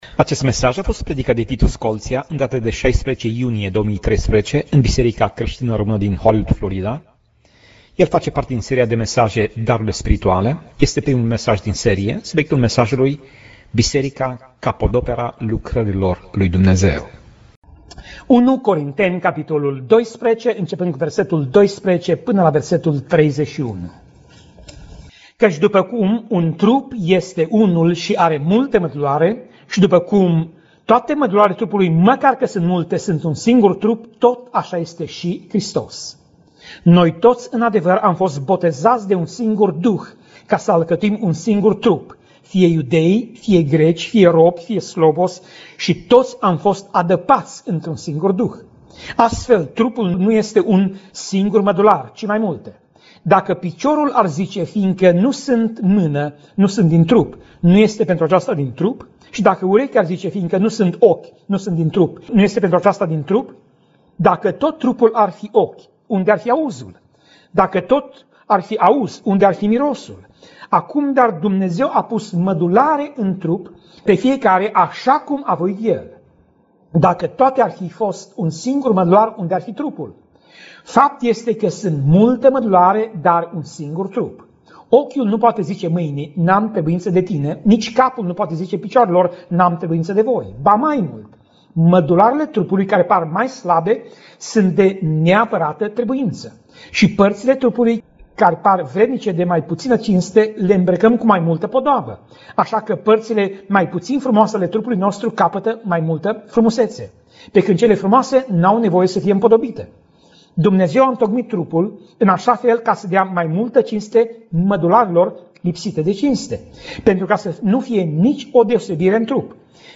Pasaj Biblie: 1 Corinteni 12:12 - 1 Corinteni 12:31 Tip Mesaj: Predica